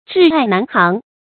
窒碍难行 zhì ài nán xíng
窒碍难行发音